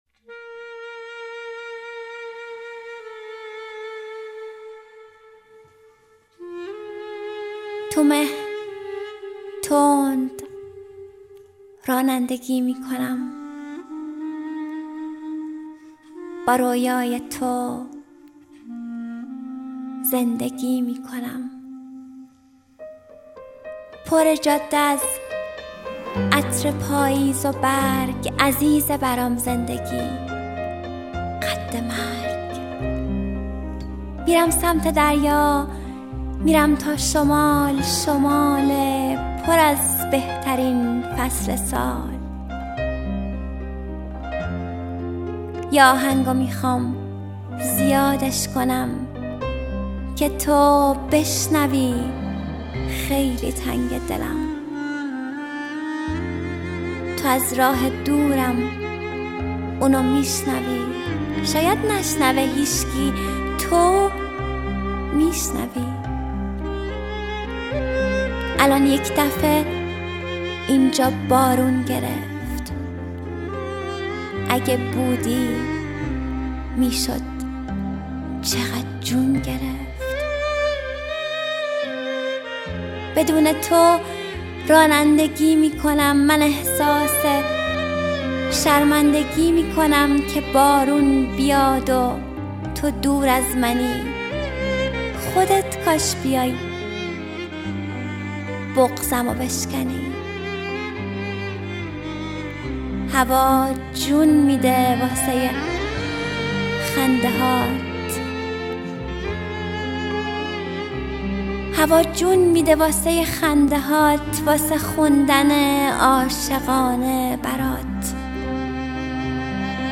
دانلود دکلمه جاده بی تو با صدای مریم حیدرزاده
گوینده :   [مریم حیدرزاده]